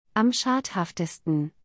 /ˈʃaːt.haft/ · /ˈʃaːt.haft/ · /ˈʃaːt.haftɐ/ · /ˈʃaːt.haft.ə.stən/